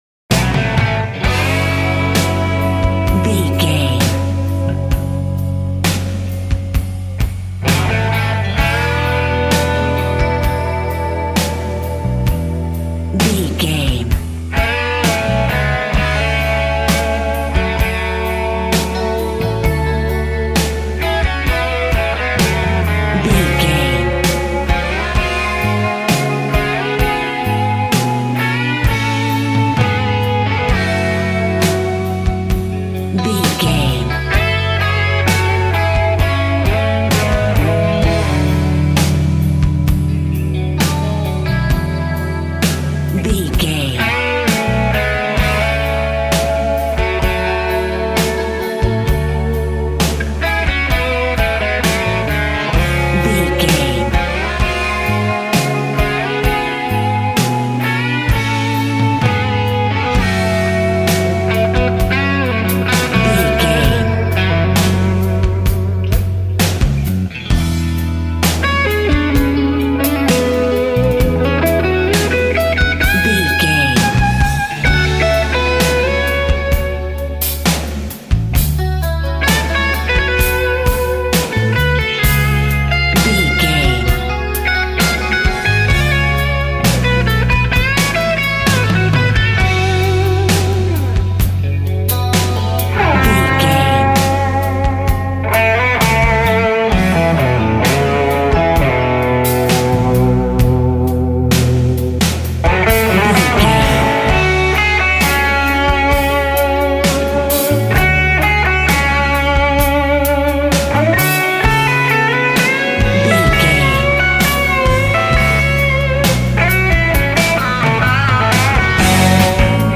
Uplifting
Aeolian/Minor
F#
Slow
drums
electric guitar
bass guitar
70s